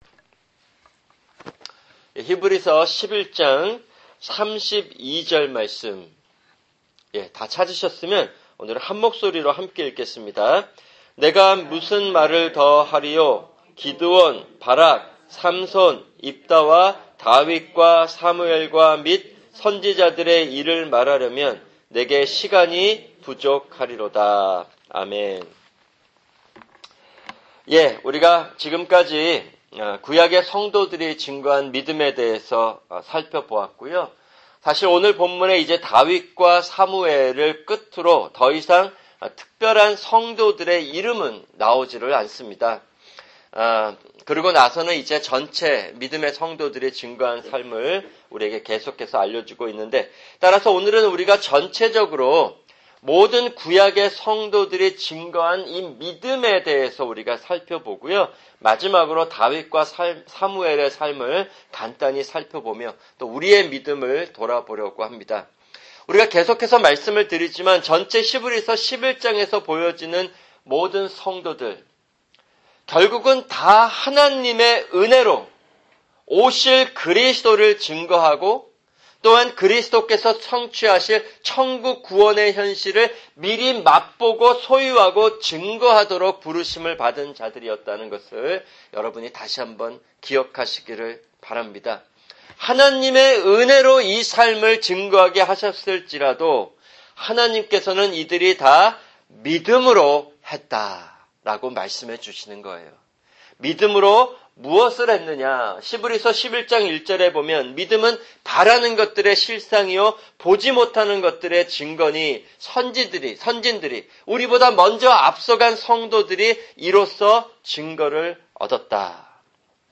[금요 성경공부] 히브리서11장(29) 11:32(5)